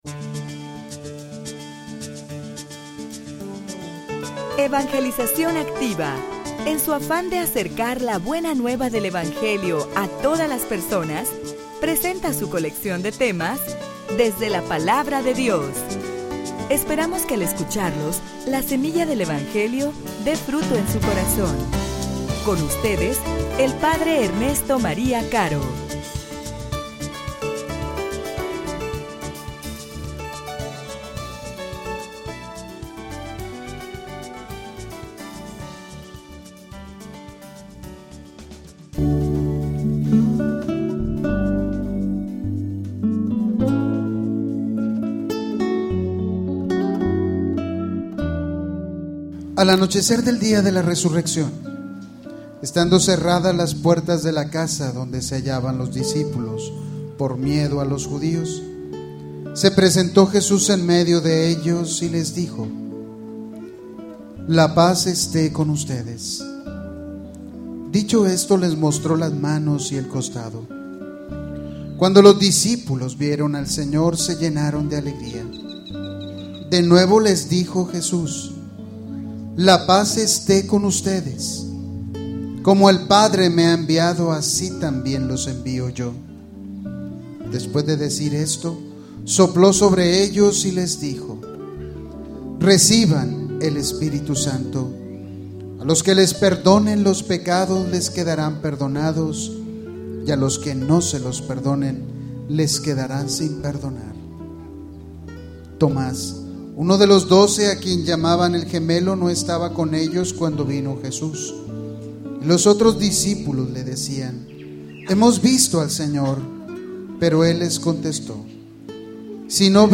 homilia_Una_fe_mas_grande_que_la_de_Tomas.mp3